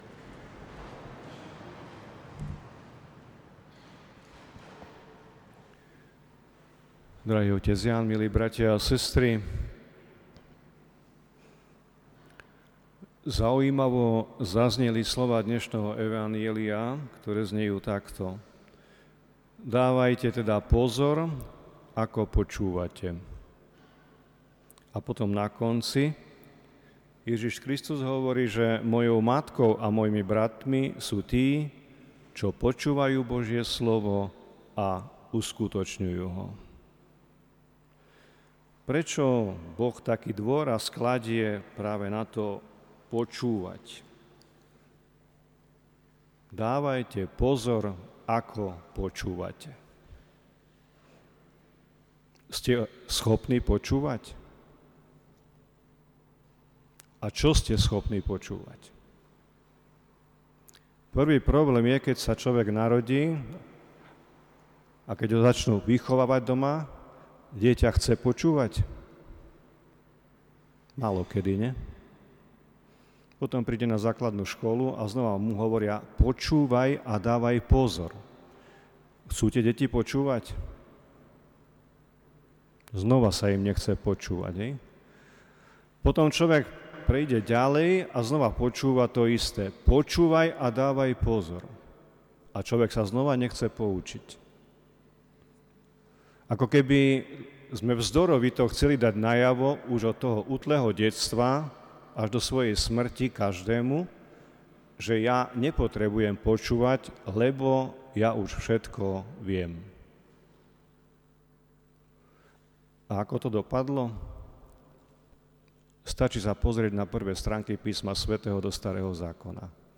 Ako každý mesiac aj dnes sa konala spomienková slávnosť ku cti blahoslaveného Metoda.